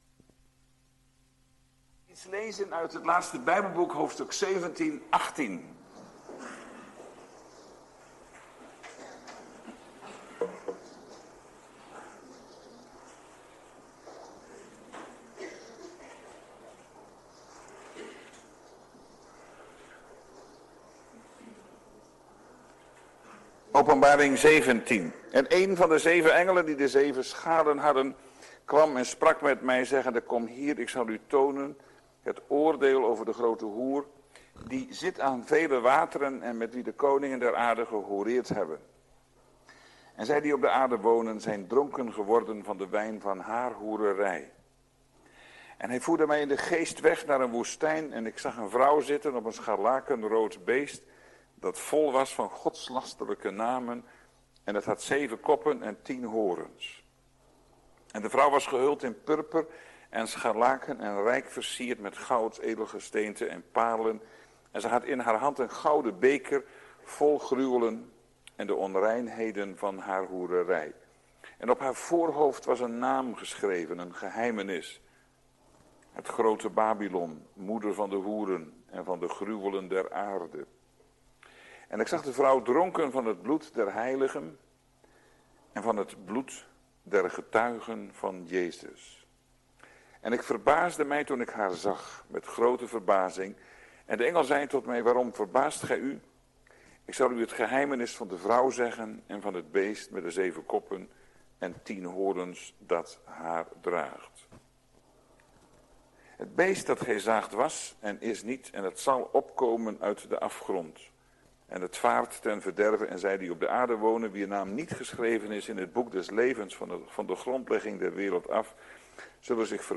Na ongeveer 45 minuten (wanneer kant A overgaat naar kant B) kan een korte stilte voorkomen.